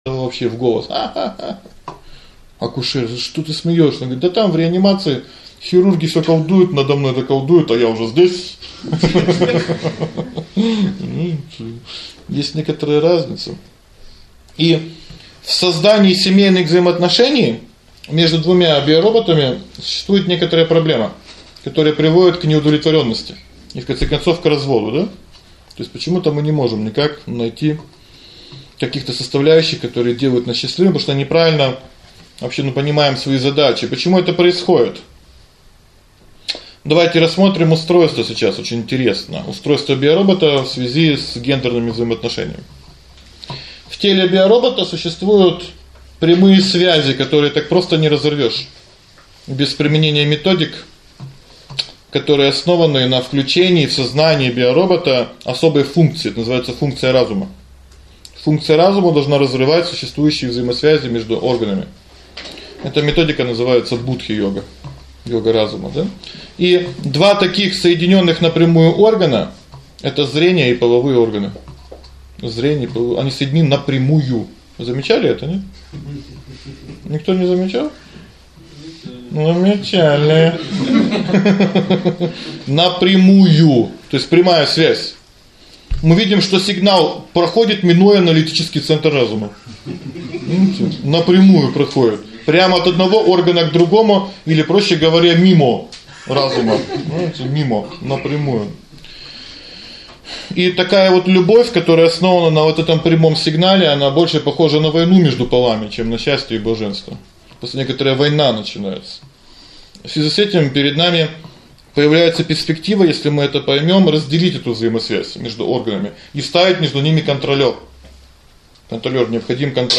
Аудиокнига : Я Биоробот